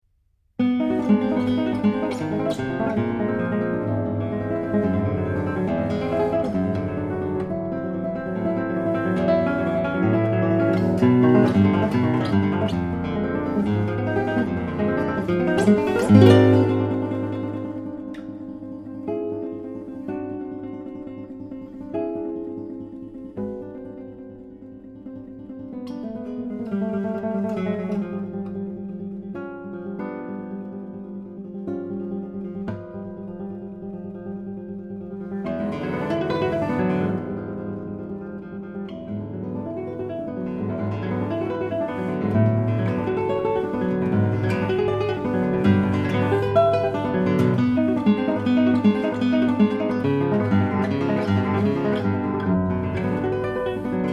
written until 2018 in impressionistic style.
Guitar